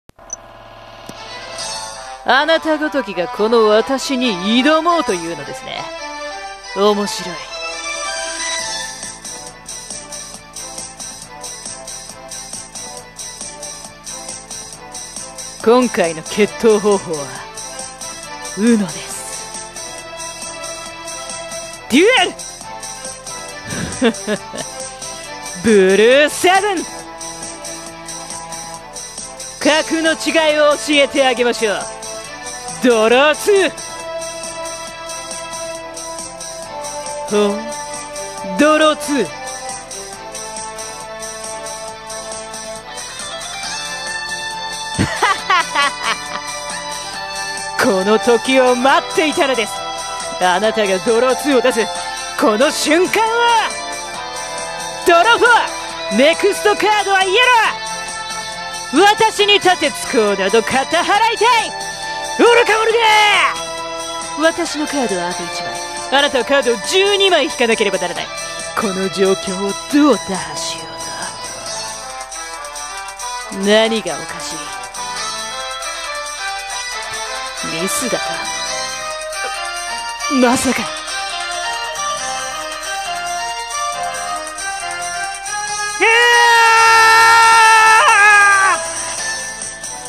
【声劇】闇のデュエルは終わらない